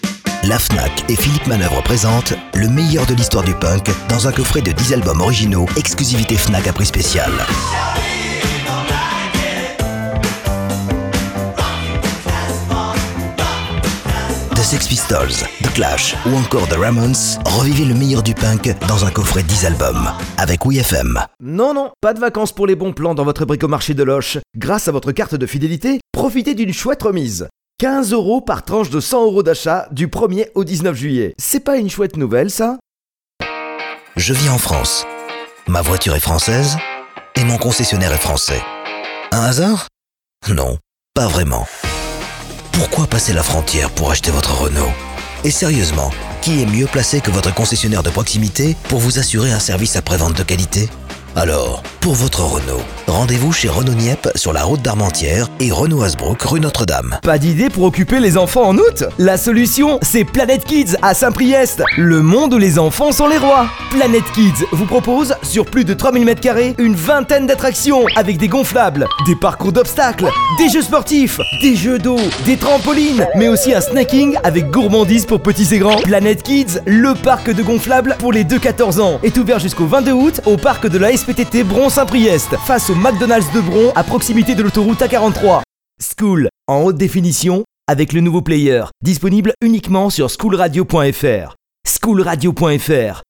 Extraits voix
Basse Baryton